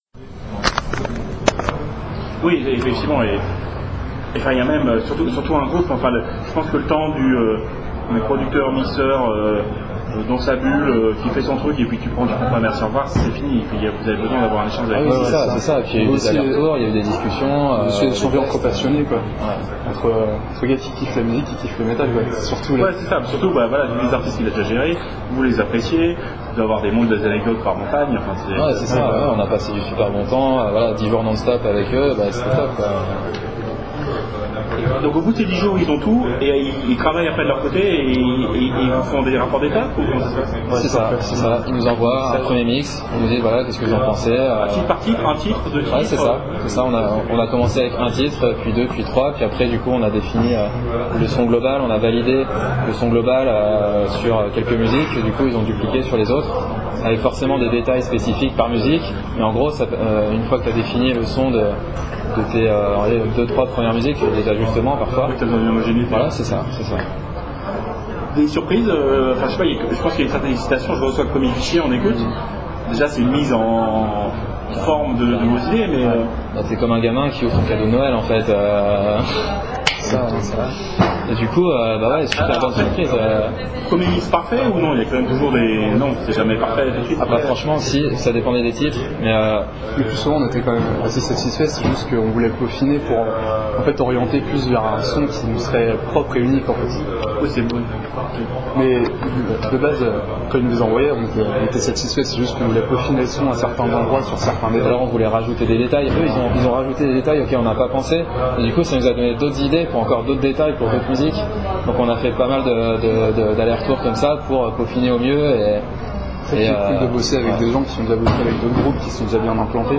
INSOLVENCY (interview